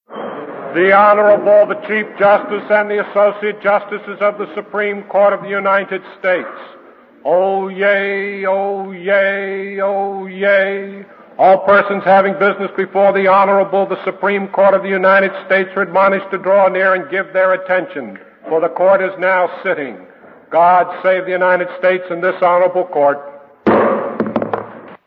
Traditional Opening of Supreme Court Sessions -
oyez.mp3